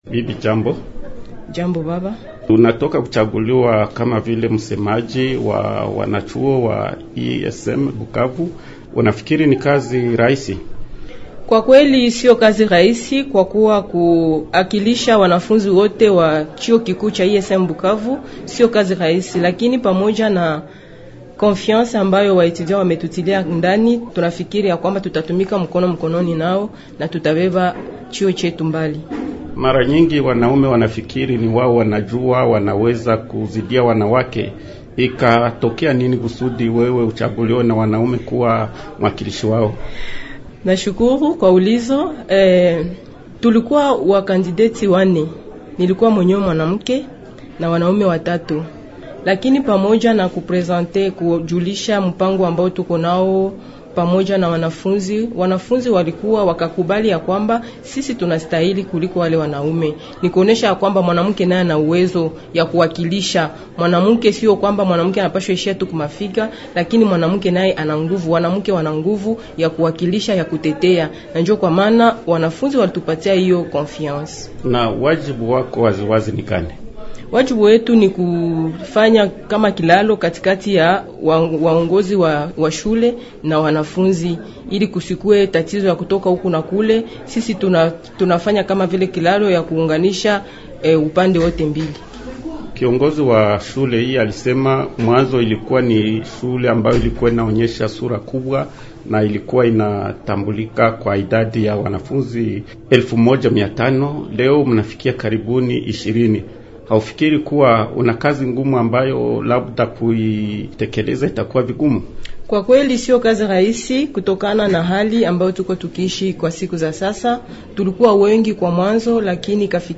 Anazungumza na Redio Okapi.